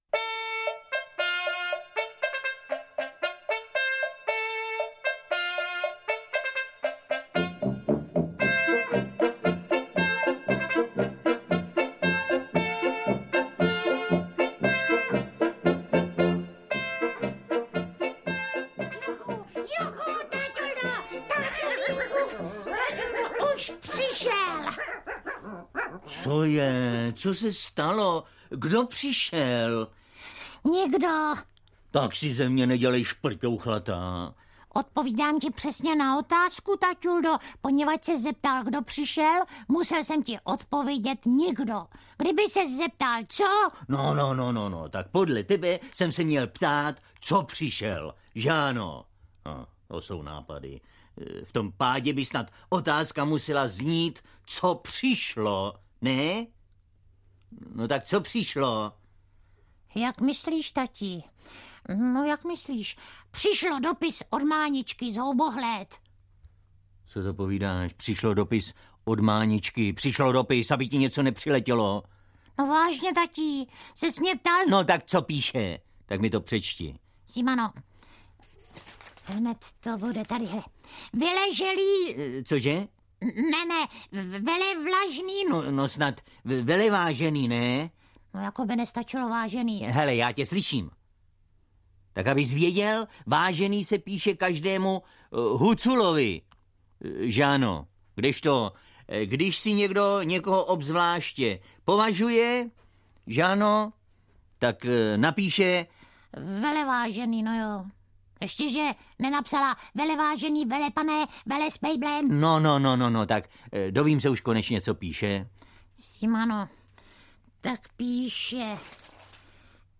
Hurvínkovy večerníčky pro kluky a pro holčičky (1) - Miloš Kirschner, Vladimír Straka - Audiokniha
• Čte: Miloš Kirschner, Helena Stachová